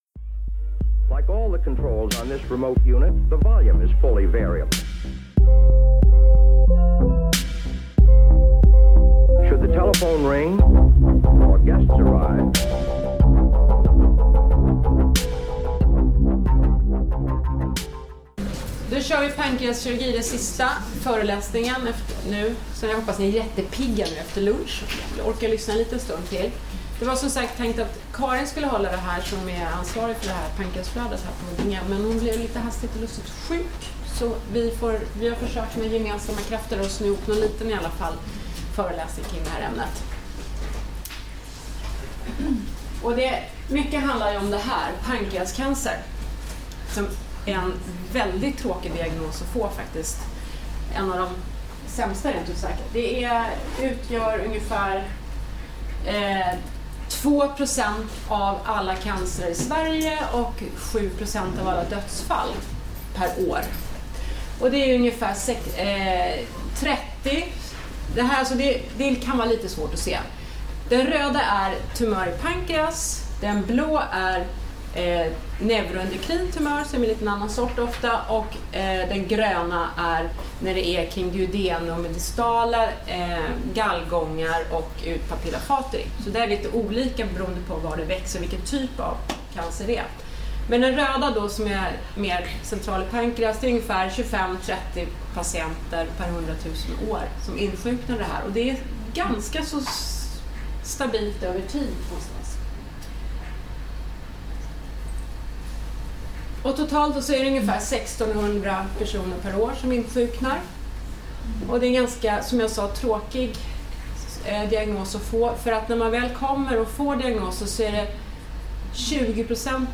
föreläser om anestesi vid pancreaskirurgi, på ST-fredagen om övre abdominell kirurgi den 16:e november 2018 på Karolinska Huddinge.